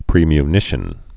(prēmy-nĭshən)